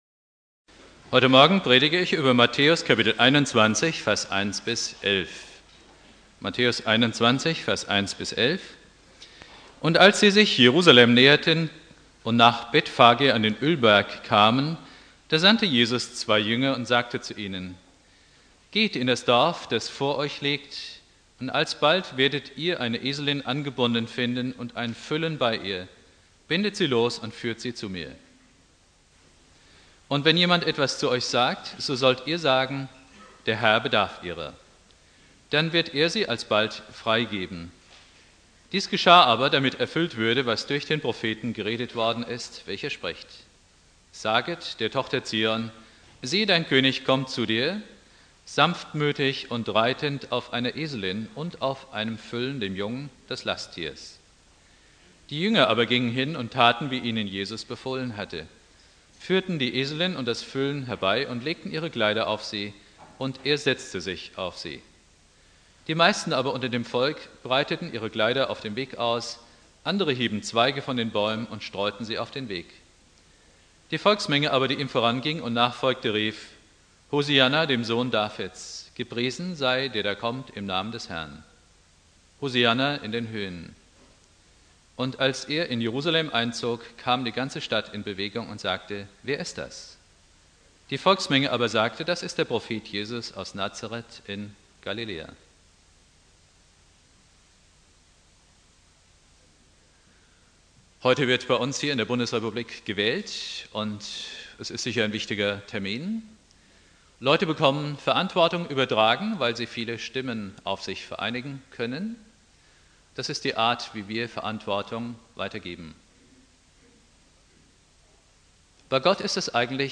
Predigt
1.Advent